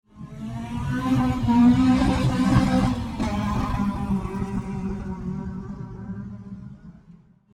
Download Cars sound effect for free.
Cars